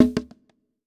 TC PERC 12.wav